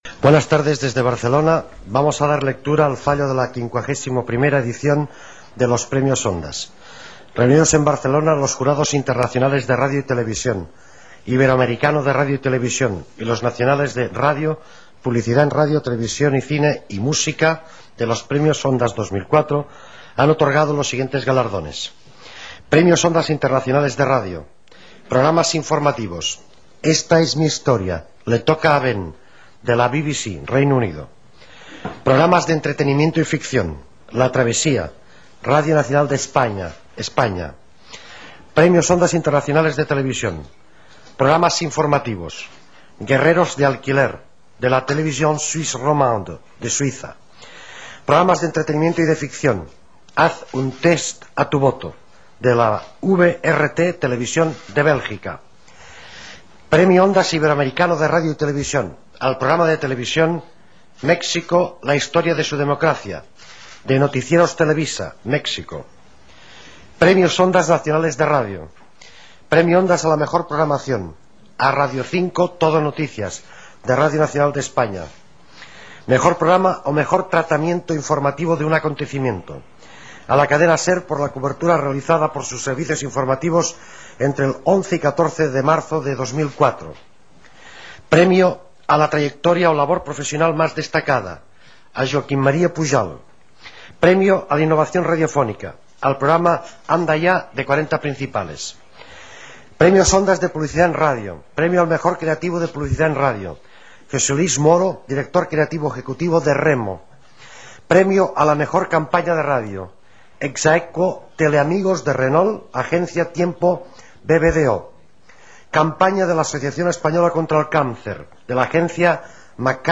Lectura de l'acta del jurat del veredicte dels 51ens Premios Ondas Gènere radiofònic Informatiu